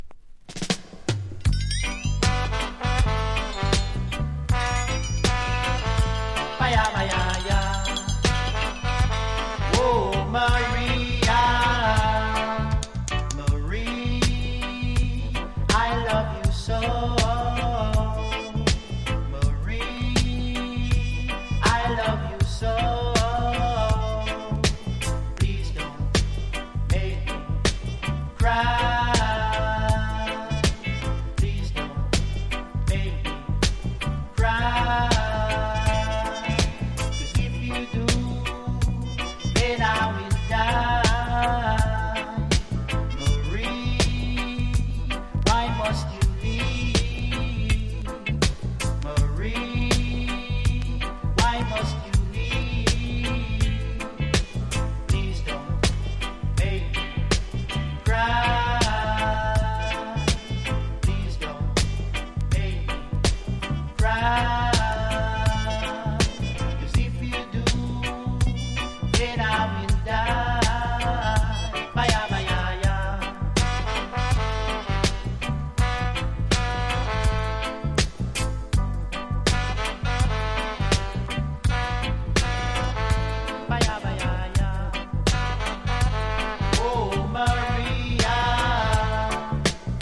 全編ロックステディ・ライクな歌のスムースさとスラロビの締まったビートのコントラストが気持ちいい傑作盤！